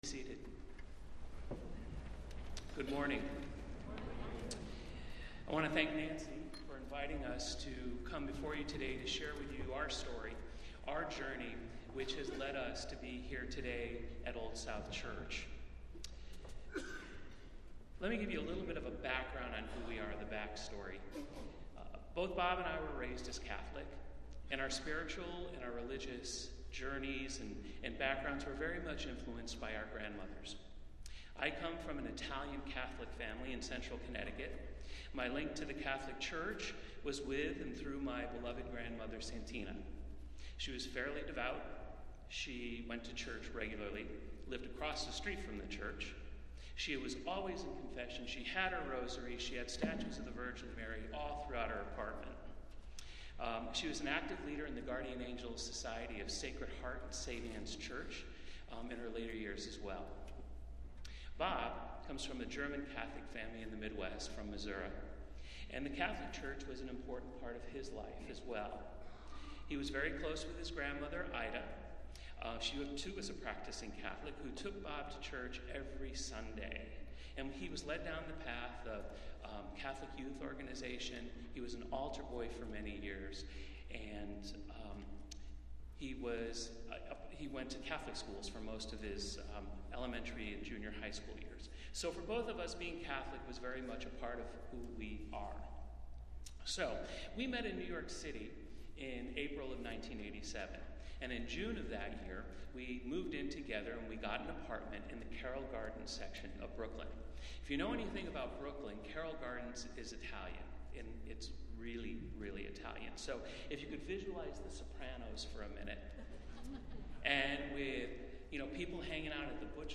Festival Worship - First Sunday after Epiphany